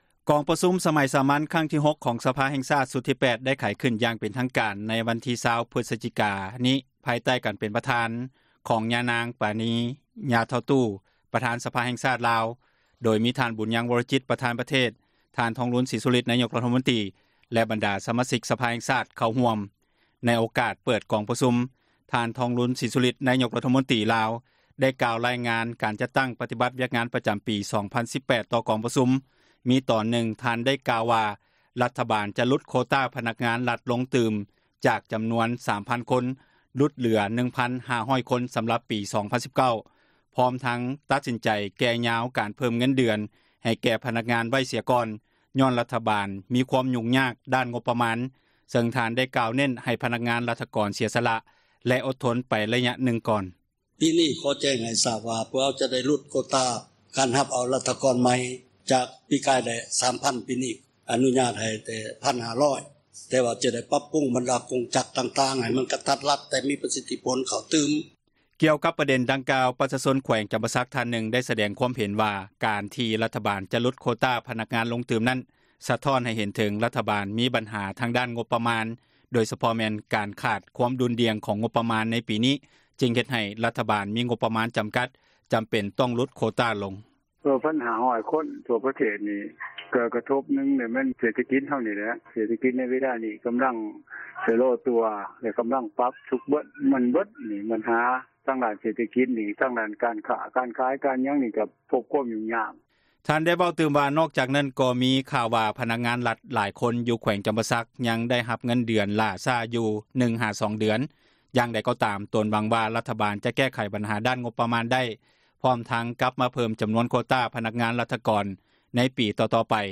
ທ່ານ ທອງລຸນ ສີສຸລິດ ນາຍົກຣັຖມົນຕຣີ ສປປ ລາວ ກ່າວໃນກອງປະຊຸມ ສະພາແຫ່ງຊາດສໄມສາມັນ ຄັ້ງທີ 6